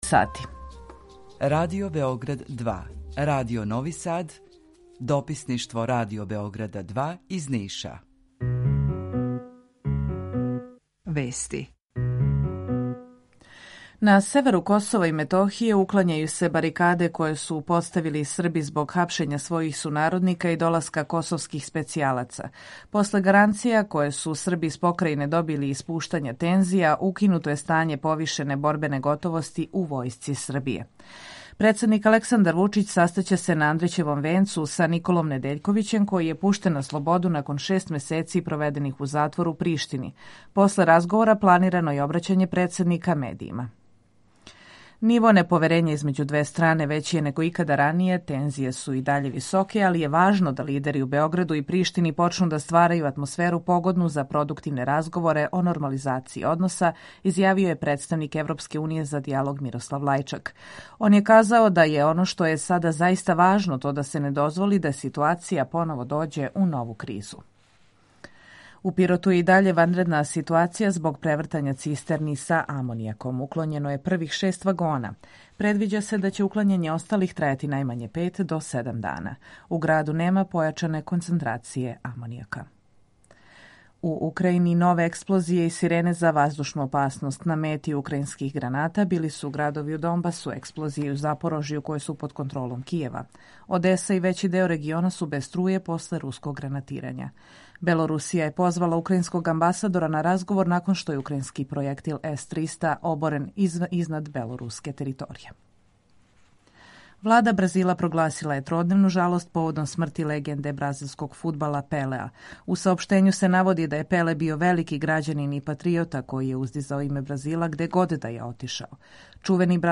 Емисију реализујемо уживо ‒ заједно са Радиом Републике Српске у Бањалуци и Радио Новим Садом
У два сата, ту је и добра музика, другачија у односу на остале радио-станице.